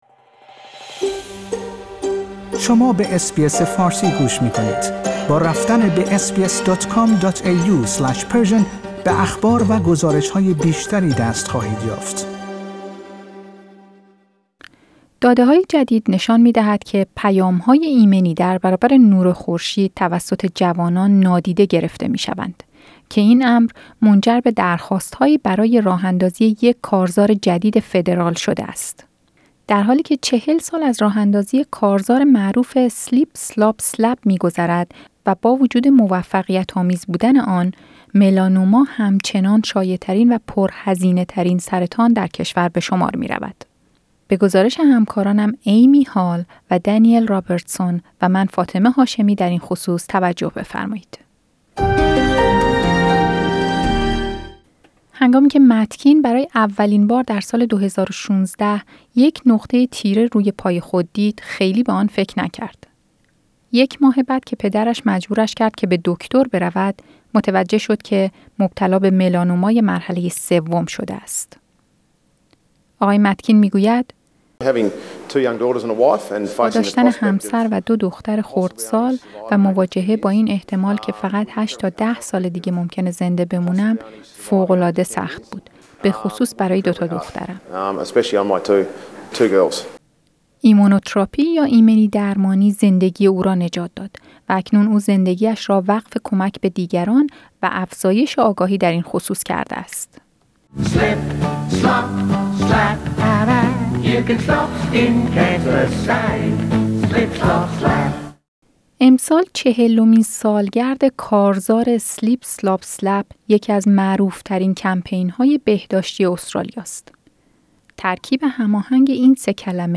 [[صدای آگهی کارزار Slip Slop Slap مربوط به دهه ۱۹۸۰]] امسال چهلمین سالگرد «Slip Slop Slap»، یکی از معروفترین کمپین‌های بهداشتی استرالیا است.